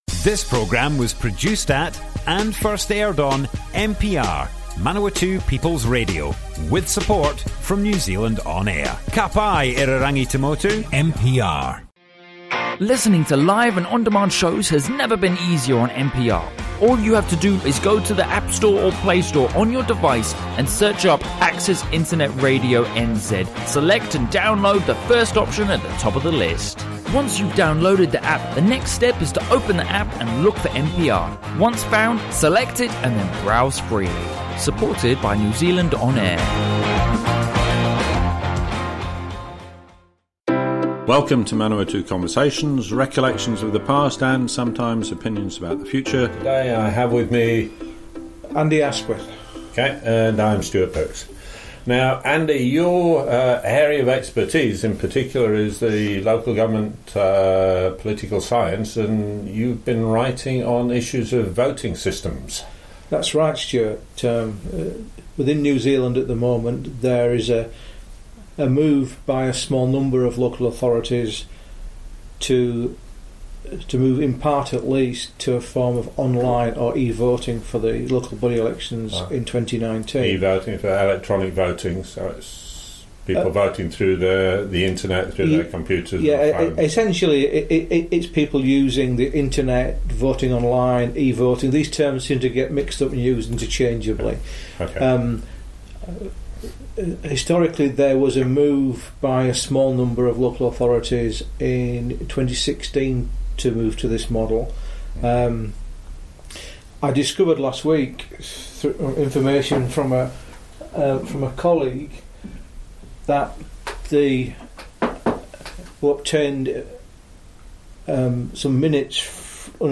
Broadcast on Manawatu People's Radio 29 January 2019.